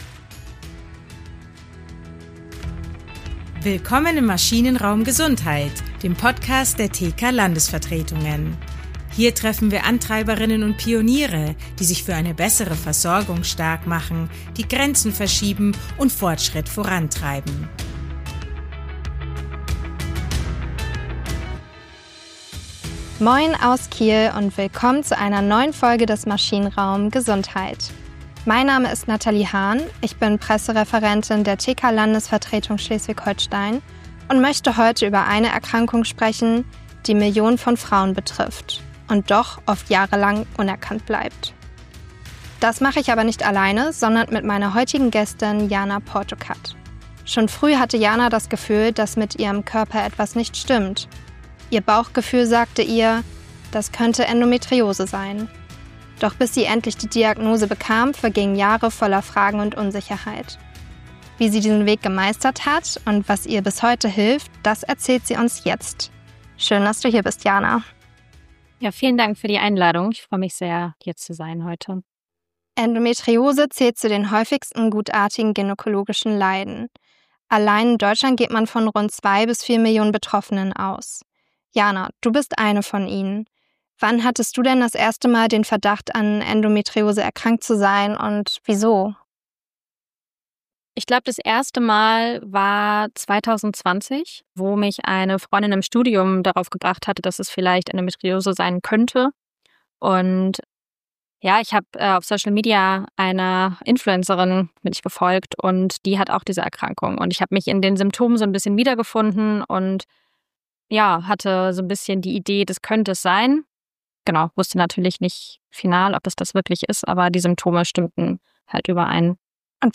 Ein Gespräch, das Mut macht und zeigt, wie wichtig ein selbstbestimmter Umgang mit der eigenen Gesundheit ist.